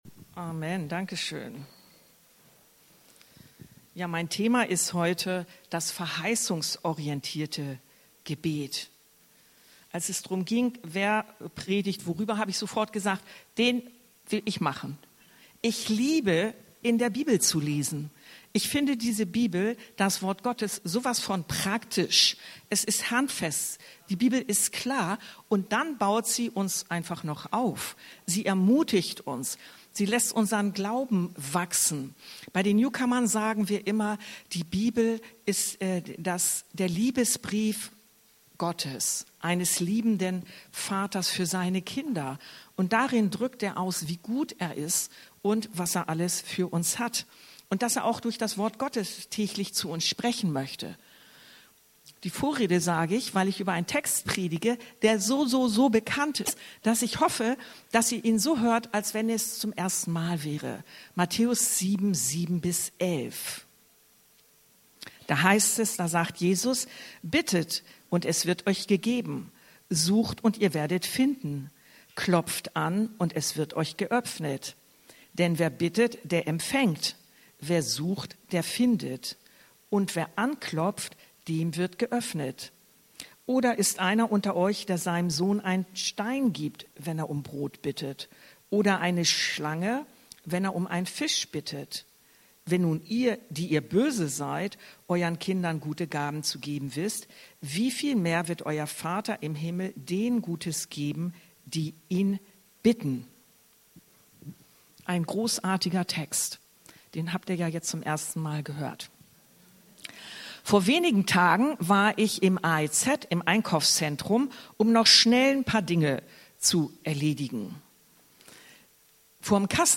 Predigtreihe: Gebet, Teil 5: Verheißungsorientiertes Gebet ~ Anskar-Kirche Hamburg- Predigten Podcast